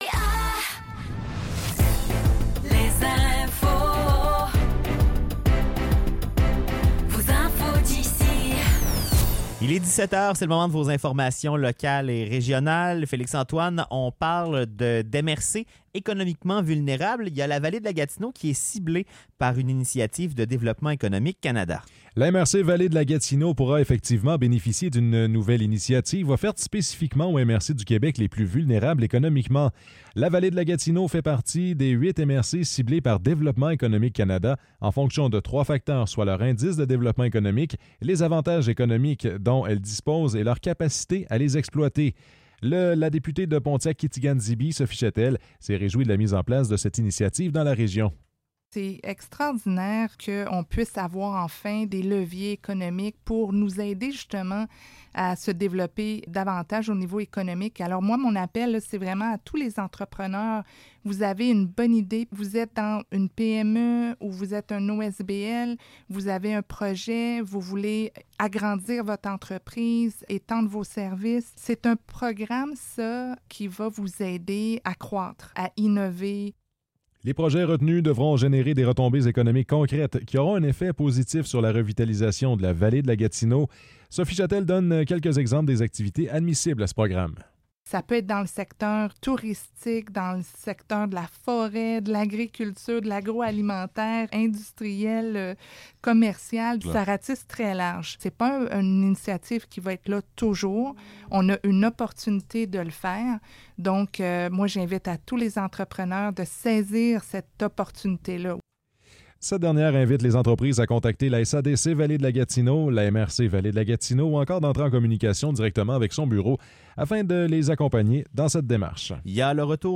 Nouvelles locales - 27 Août 2024 - 17 h